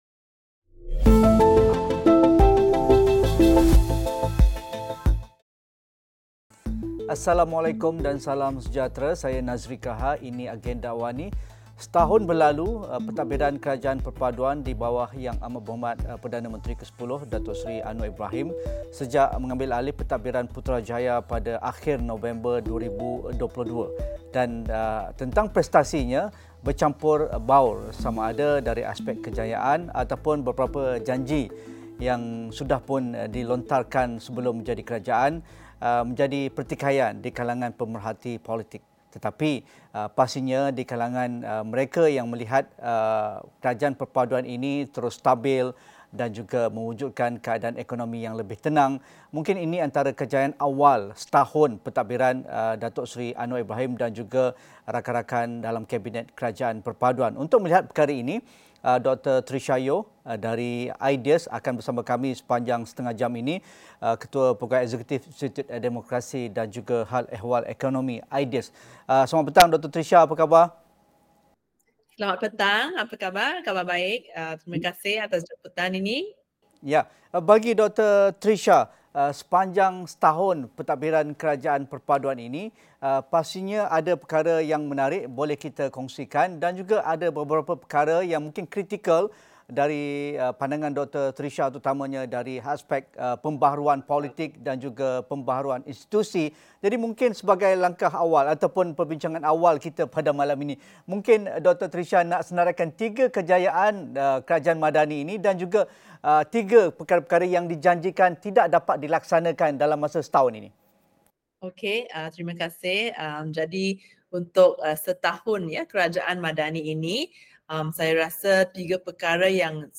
Tumpuan mengenai desakan reformasi institusi dan pembaharuan politik, kenapa ia wajar disokong dan apa reformasi lain yang perlu disegerakan? Diskusi 9 malam